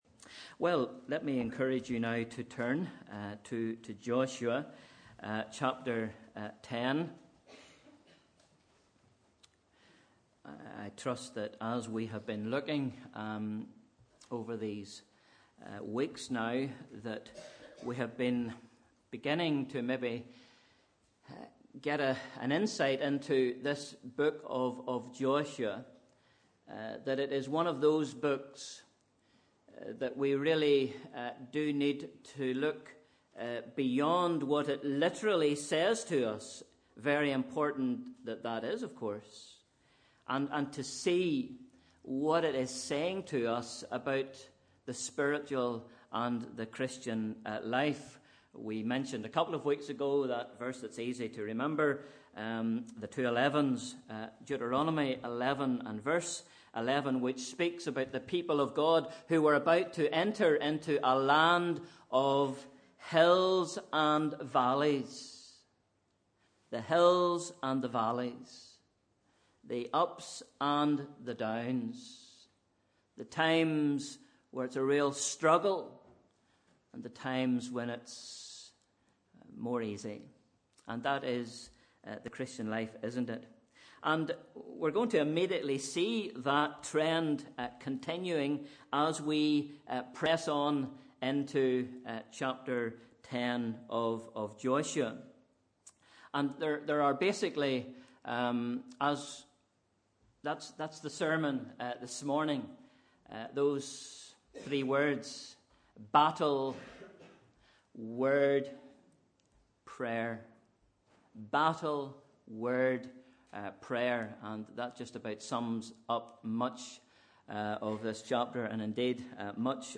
Sunday 11th December 2016 – Morning Service